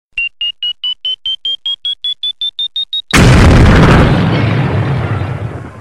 Nada dering Bom waktu
Keterangan: Unduh nada dering Bom Waktu, suara Bom Ledakan dalam format MP3 untuk WhatsApp Anda.
nada-dering-bom-waktu-id-www_tiengdong_com.mp3